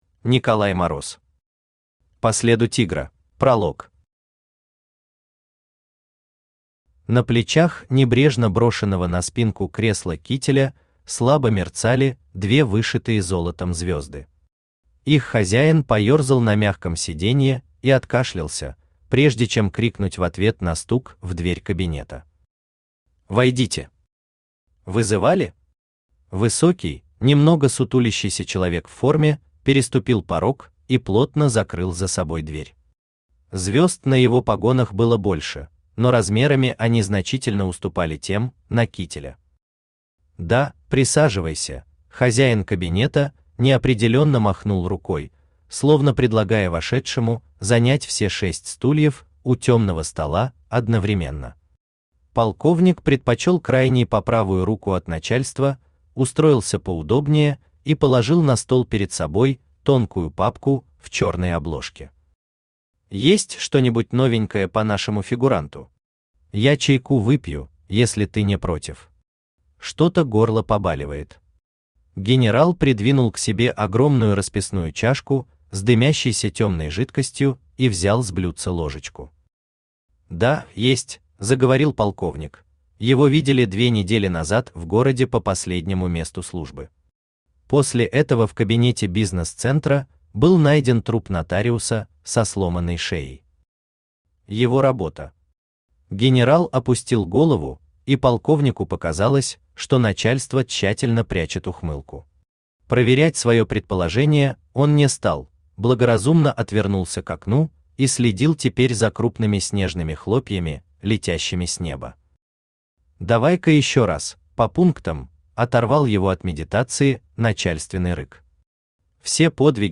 Aудиокнига По следу тигра Автор Николай Мороз Читает аудиокнигу Авточтец ЛитРес.